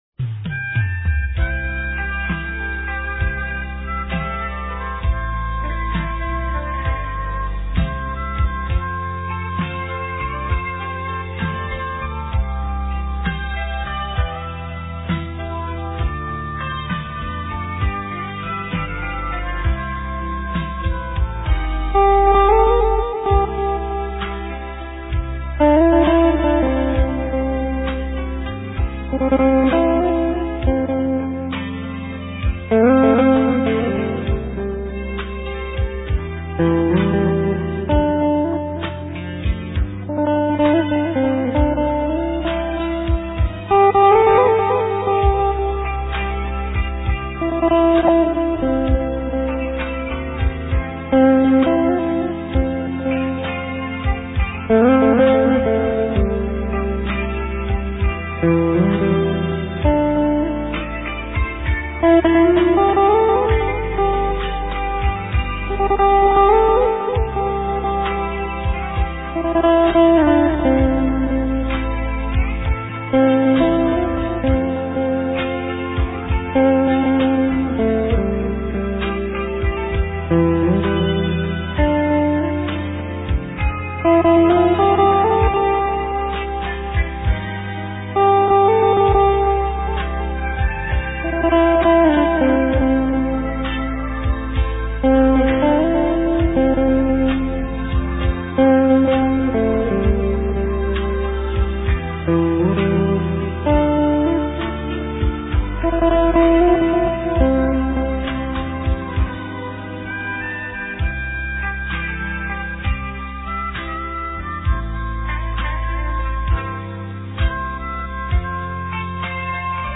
Ca sĩ: Không lời